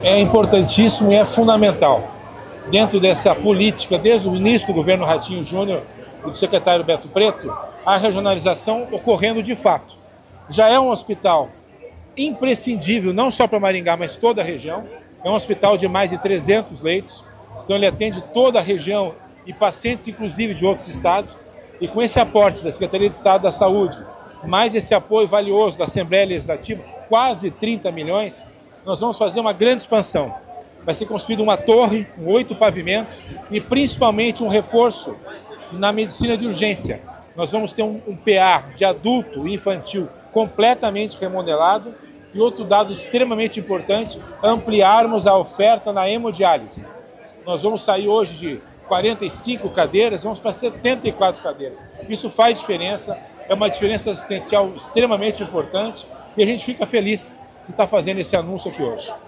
Sonora do diretor-geral da Secretaria da Saúde, César Neves, sobre o investimento de R$ 28 milhões para novo prédio da Santa Casa de Maringá